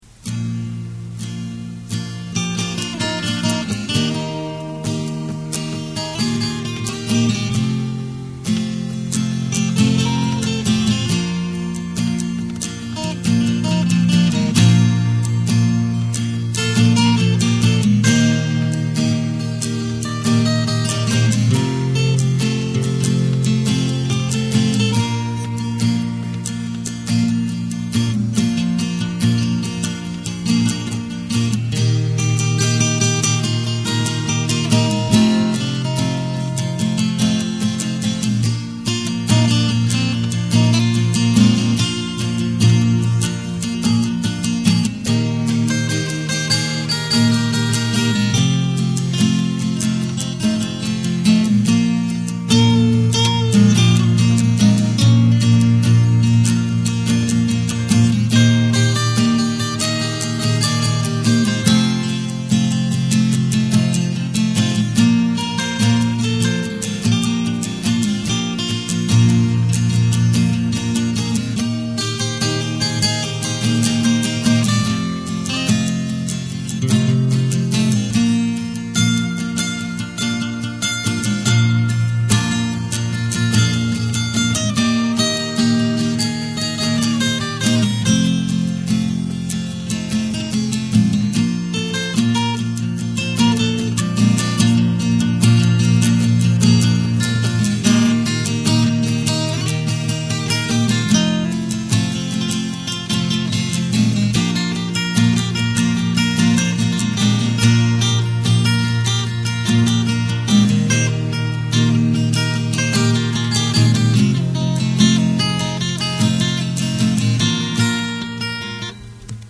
J' ai acheté en 1986 un petit studio 4 pistes à cassette (un porta one de chez Tascam pour les techniciens)
Bien sur la qualité sonore n'est pas terrible et la justesse de l'orchestration, l'exécution des morceaux
2'00 encore un trio à corde